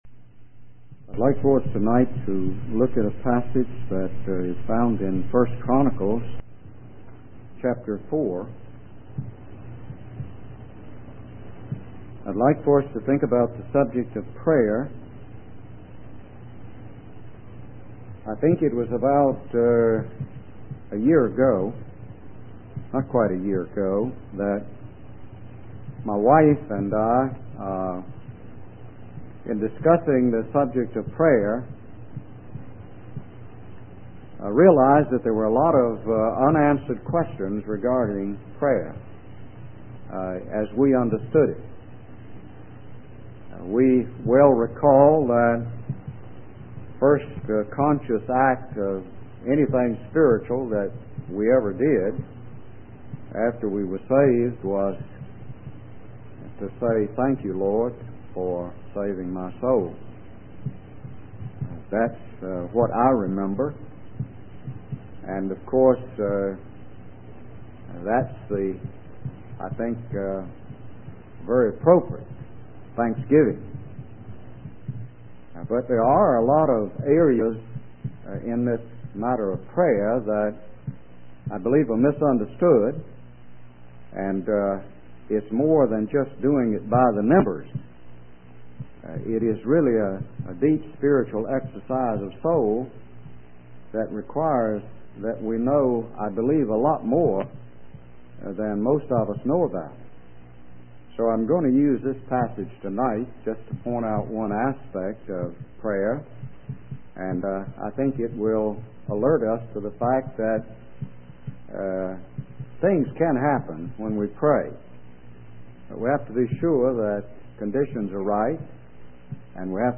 In this sermon, the preacher discusses the story of Jabez from 1 Chronicles chapter 4.